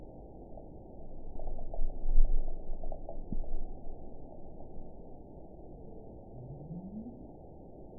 event 922160 date 12/27/24 time 20:21:12 GMT (11 months, 1 week ago) score 9.32 location TSS-AB06 detected by nrw target species NRW annotations +NRW Spectrogram: Frequency (kHz) vs. Time (s) audio not available .wav